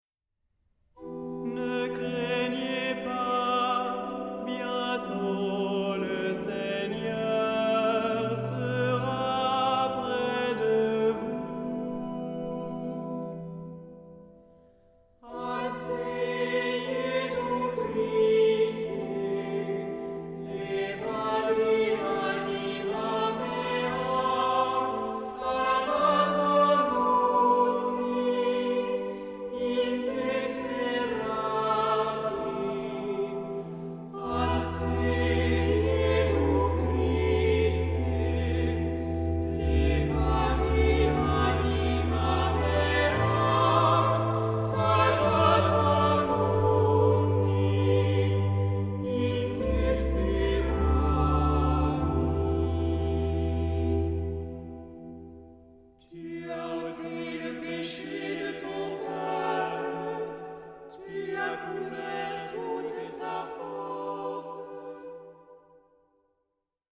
SAH O SATB (4 voces Coro mixto) ; Partitura general.
Salmodia.